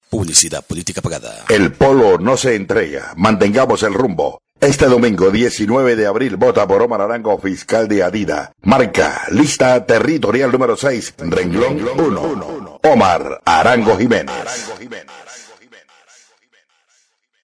Publicidad Política Pagada